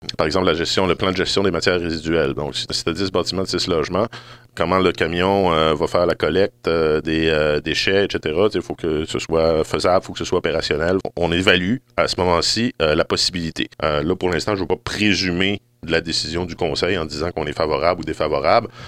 Le projet qui déroge à la réglementation devra répondre à plusieurs conditions avant d’être accepté, indique le maire Antonin Valiquette: